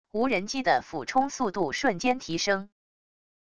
无人机的俯冲速度瞬间提升wav音频